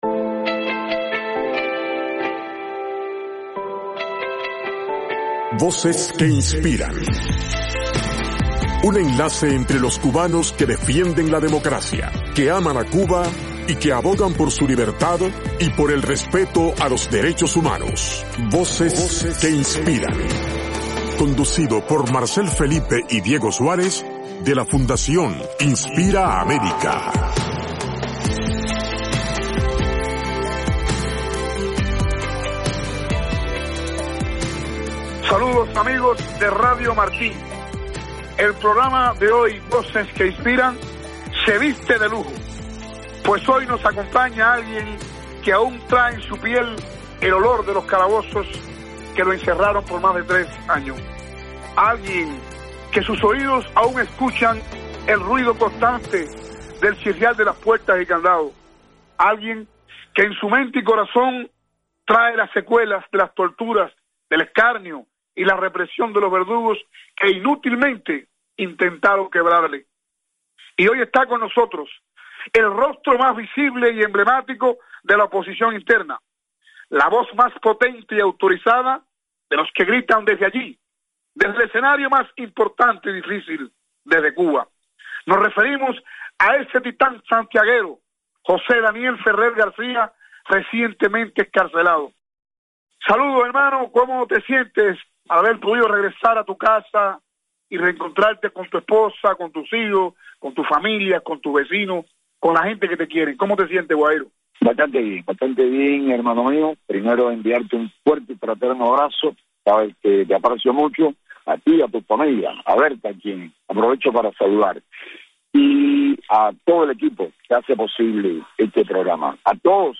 Entrevista con José Daniel Ferrer